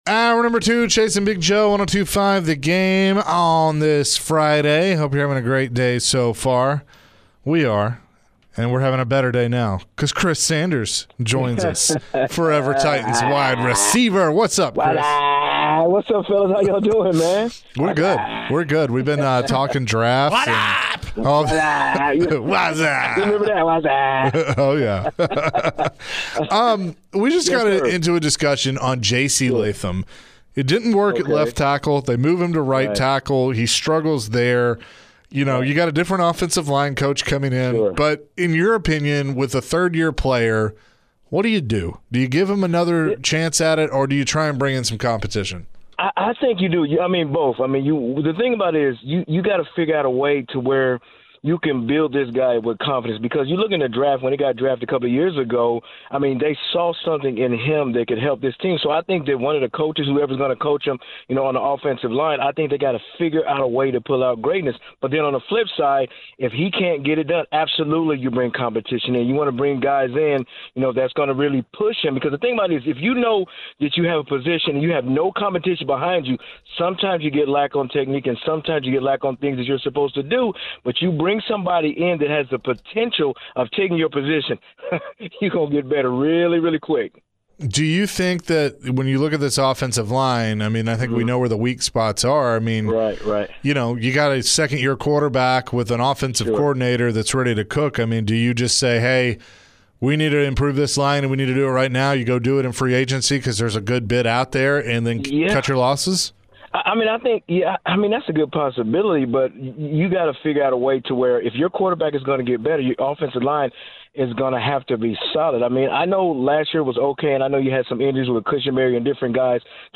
Forever Titans WR Chris Sanders joined the show and shared his thoughts on the upcoming NFL Draft. Chris also shared his thoughts on the Titans' new coaching staff.